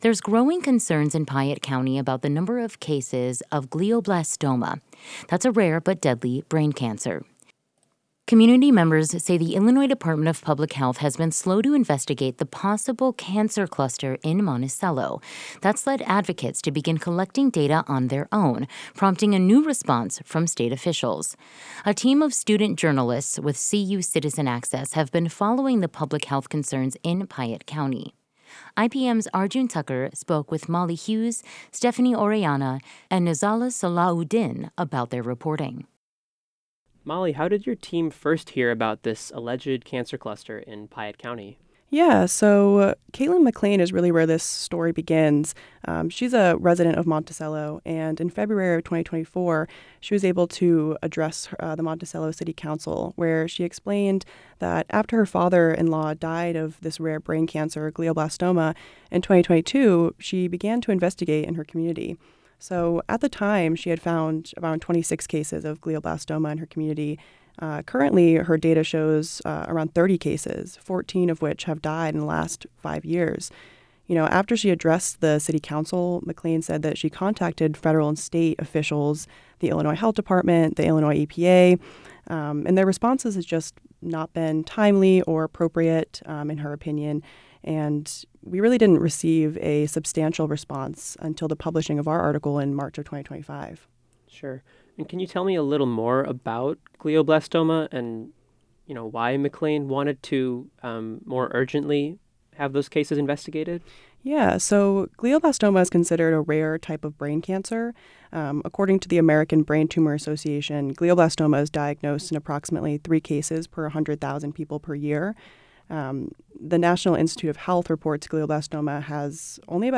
This interview has been edited for conciseness and clarity.